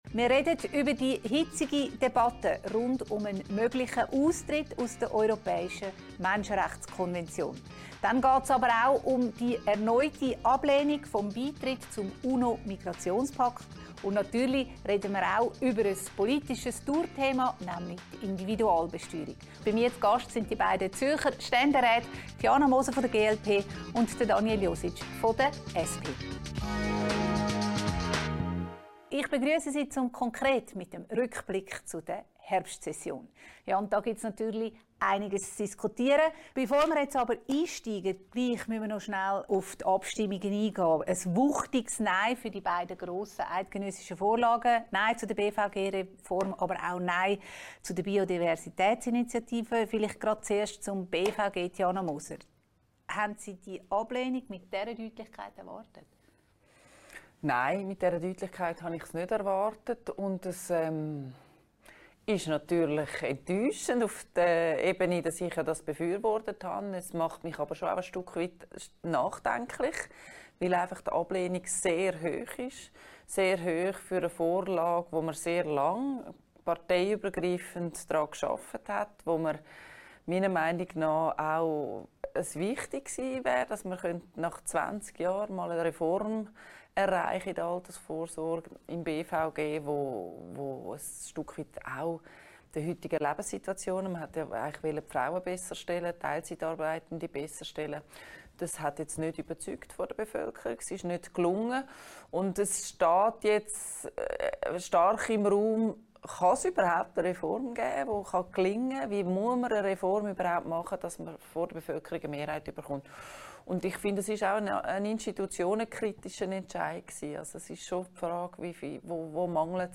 diskutiert mit den beiden Zürcher Ständeräten Tiana Moser, GLP und Daniel Jositsch, SP